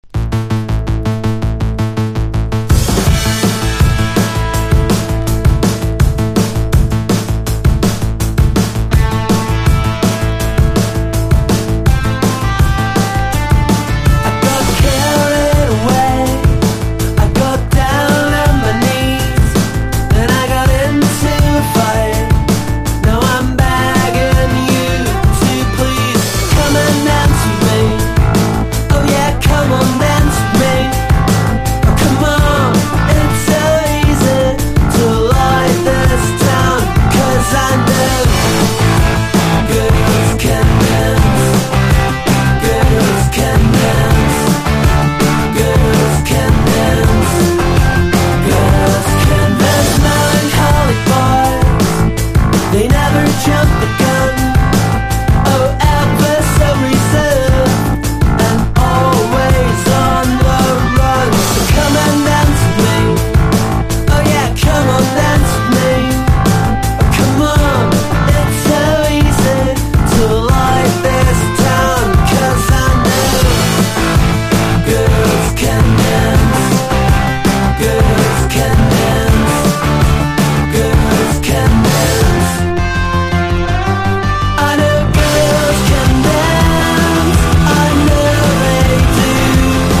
80'S的な打ち方と強めのアタックが新鮮なサウンド！